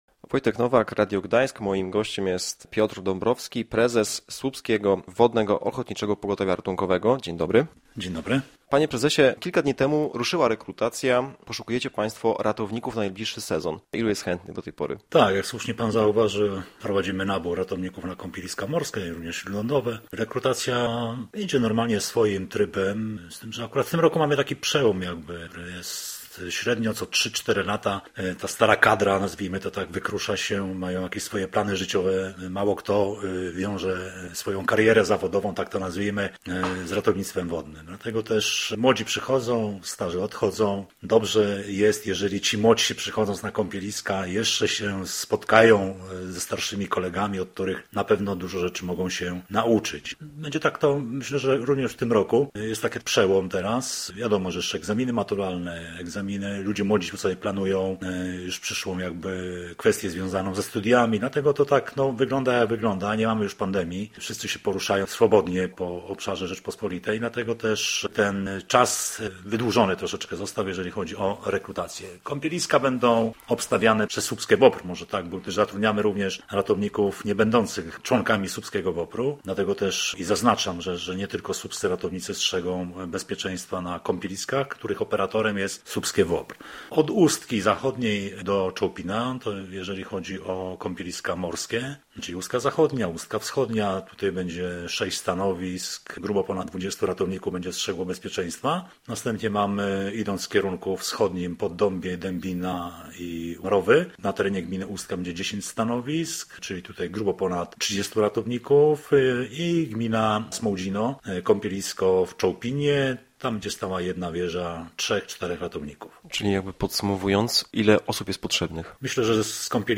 W rozmowie z naszym dziennikarzem opowiadał między innymi, jak przebiega rekrutacja ratowników do nadchodzącego sezonu i czego oczekuje się od kandydatów. Mówił też o przewinieniach, jakich najczęściej dopuszczają się plażowicze.